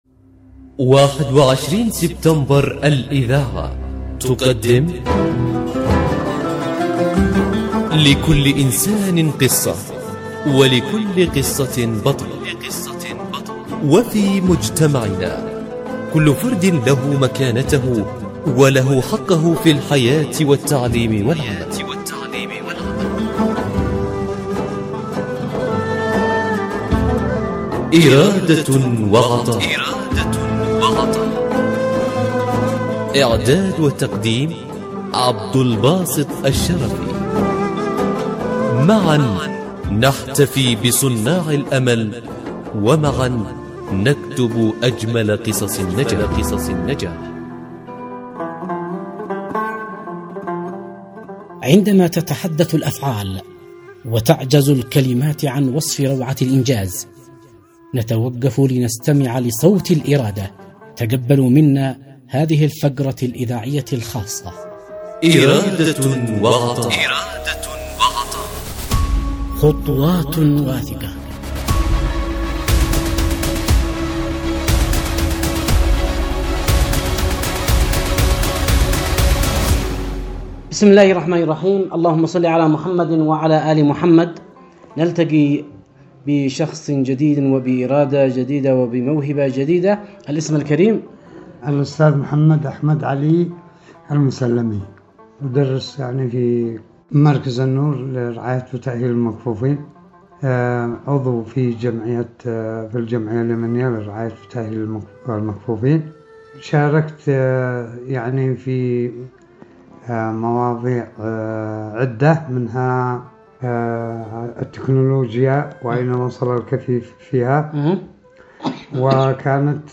برنامج “إرادة وعطاء” يأخذكم في رحلة إذاعية قصيرة ، نستكشف خلالها عالماً مليئاً بالتحدي والإصرار. نسلط الضوء على قصص ملهمة لأشخاص من ذوي الهمم، أثبتوا أن الإعاقة لا تحد من العطاء بل تزيده قوة وإبداعاً.